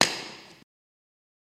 Firehose | Sneak On The Lot